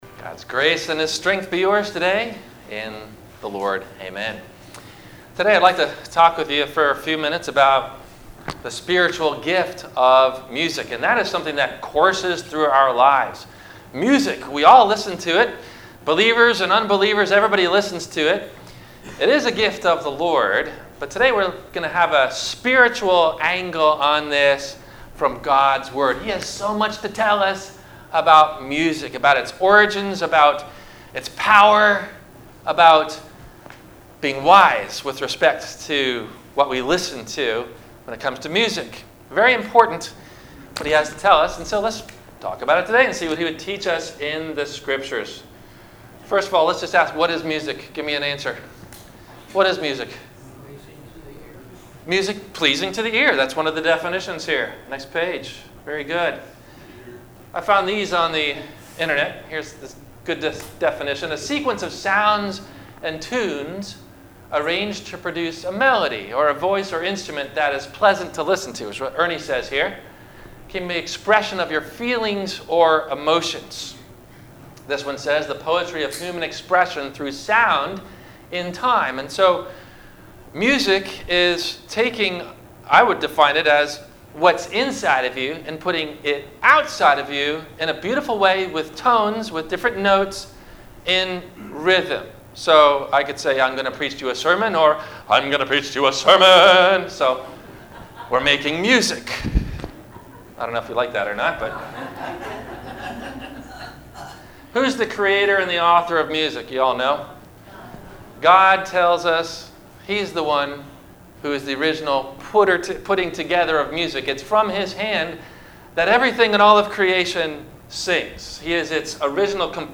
- Sermon - March 31 2019 - Christ Lutheran Cape Canaveral